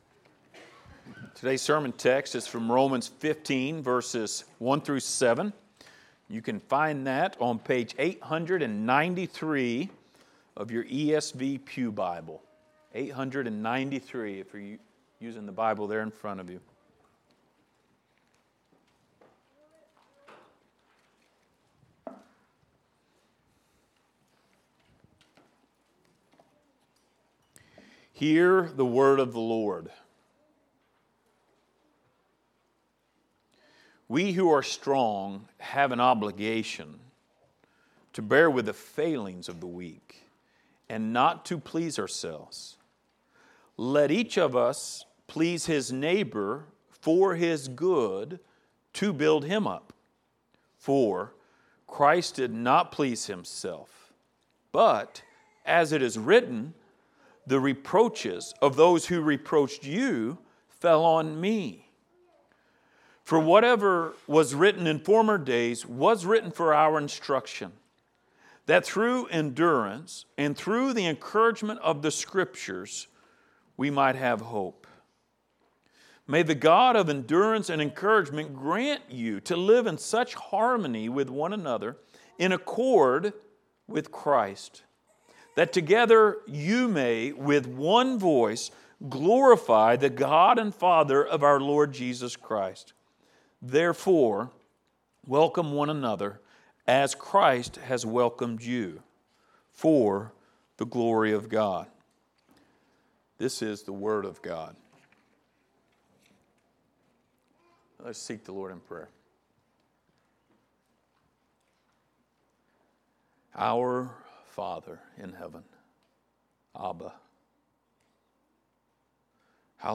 Passage: Romans 15:1-7 Service Type: Sunday Morning